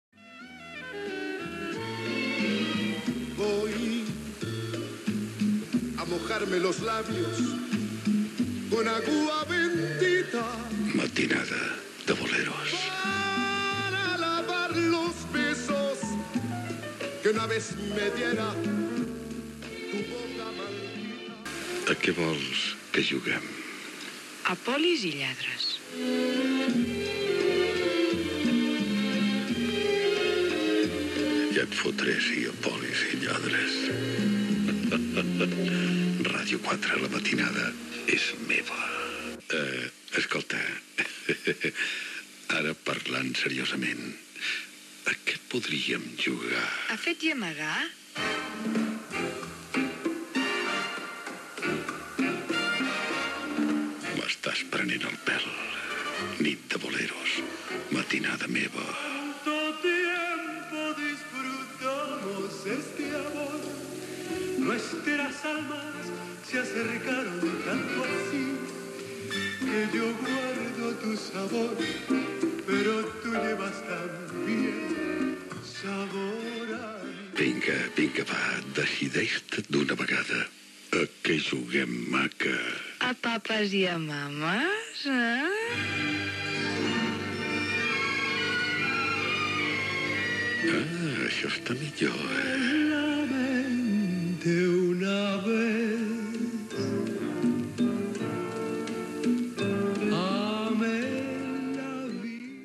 Presentació de diversos temes i diàleg radiofònic amb una dona sobre allò a que vol jugar
Musical
FM